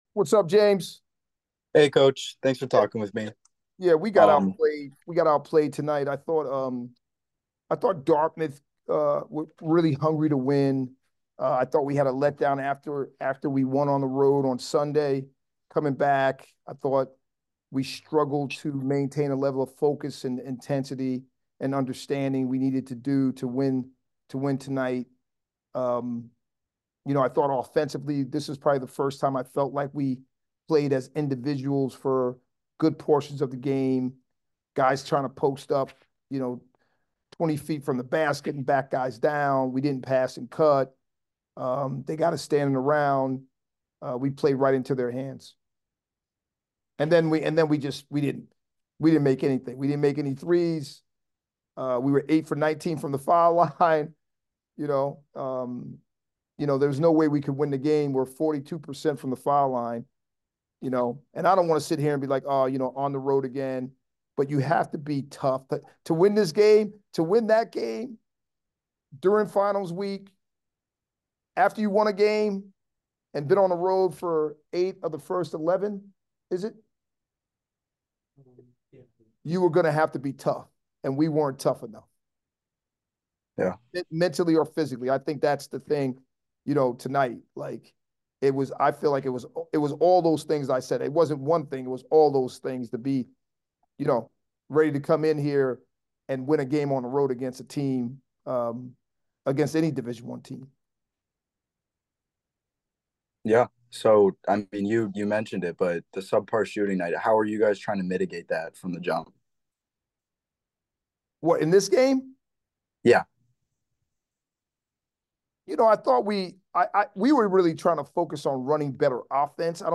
Dartmouth Postgame Interview